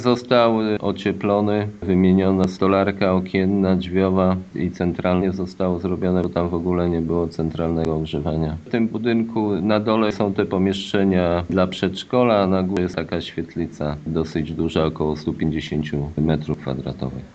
By obiekt mógł spełniać wymogi stawiane tego typu placówkom musiał być gruntownie wyremontowany – mówi wójt Jacek Anasiewicz: